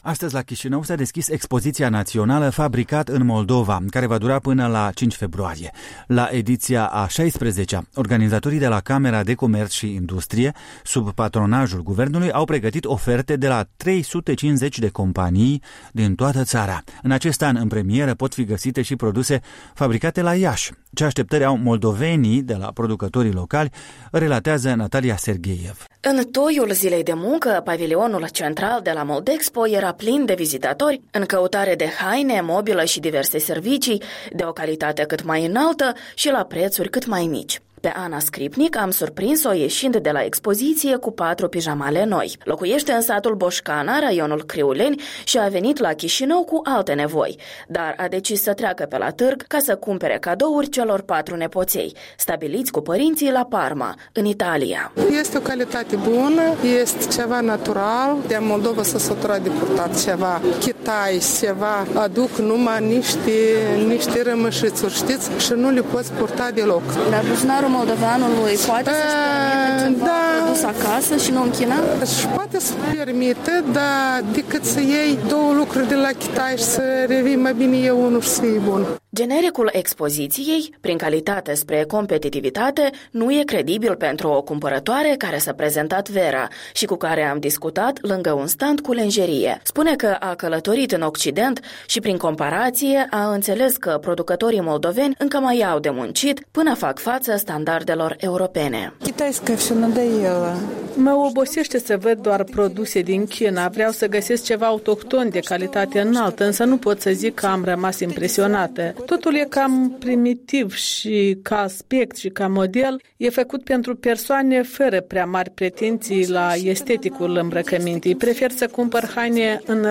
Opinii ale vizitatorilor și un generic puțin credibil: „Prin calitate spre competitivitate”.
În toiul zilei de muncă, pavilionul central de la centrul expozițional Moldexpo era plin de vizitatori în căutare de haine, mobilă și diverse servicii de o calitate cât mai înaltă și la prețuri cât mai mici.